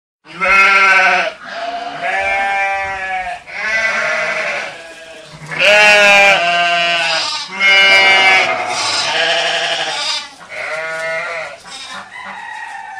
Sheep Baa klingelton kostenlos
Kategorien: Tierstimmen